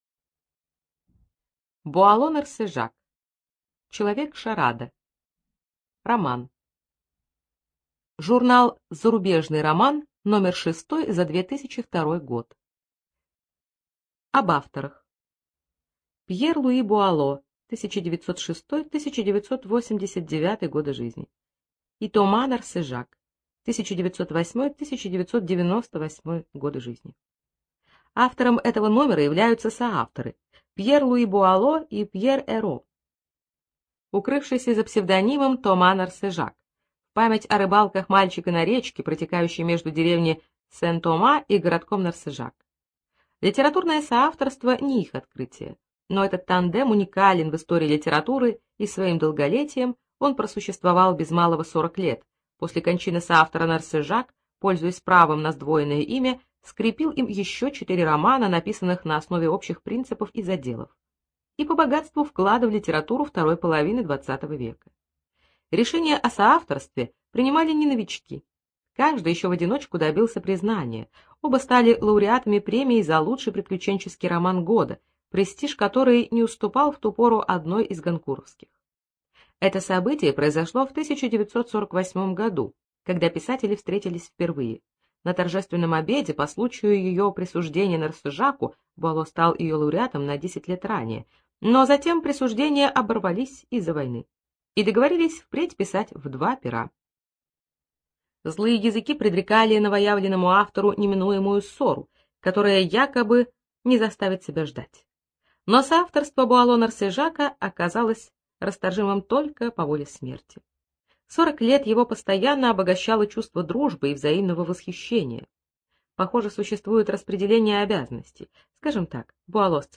ЖанрДетективы и триллеры
Студия звукозаписиКемеровская областная специальная библиотека для незрячих и слабовидящих